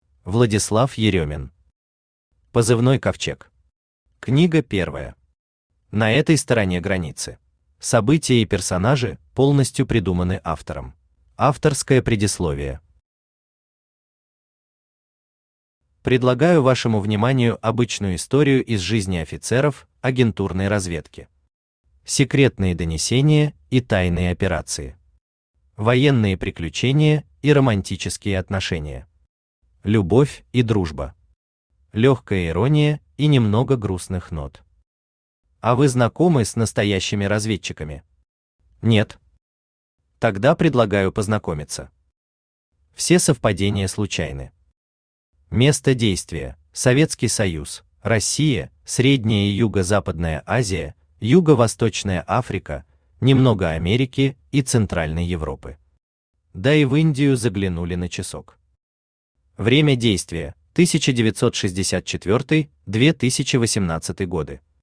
На этой стороне границы Автор Владислав Еремин Читает аудиокнигу Авточтец ЛитРес.